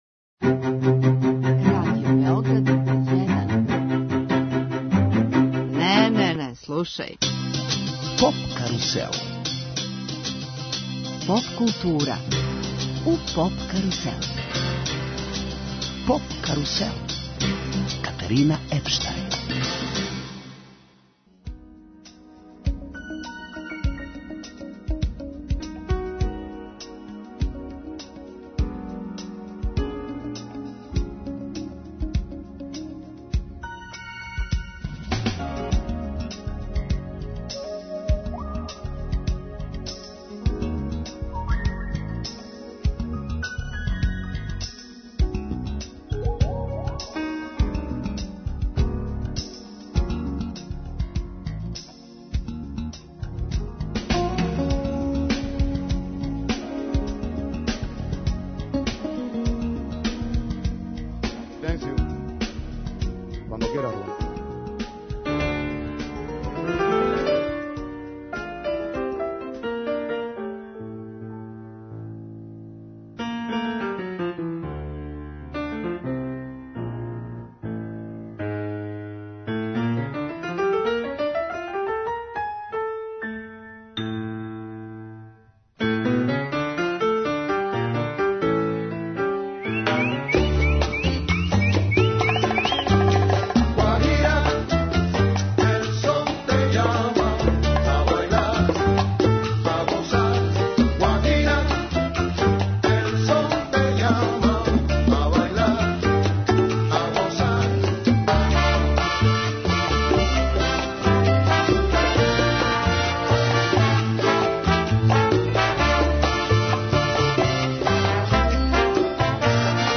Гост емисије је амбасадор Кубе у Србији, Његова екселенција Густаво Триста дел Тодо, поводом београдског концерта кубанских славних музичара Афро Кубан ол старс (Afro Cuban All Stars), који ће да се одржи под покровитељством кубанске амбасаде.